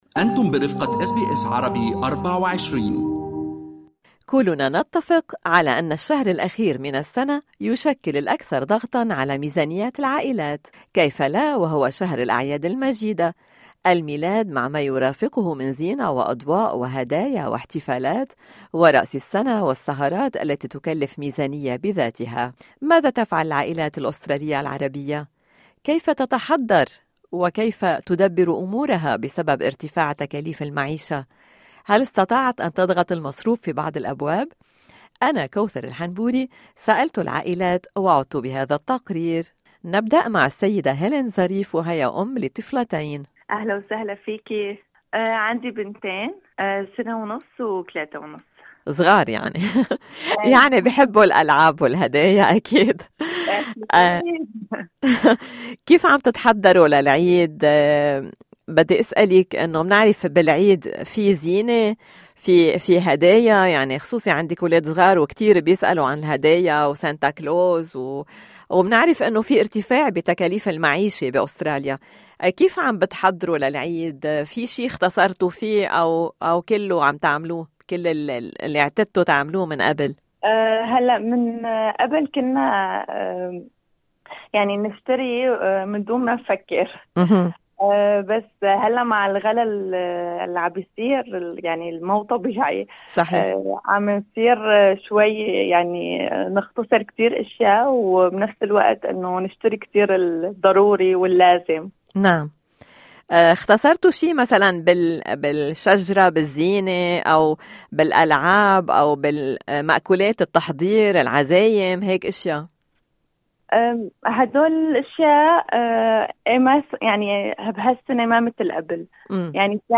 "أس بي أس عربي" سألت بعض العائلات الشابة وعادت بهذا التقرير: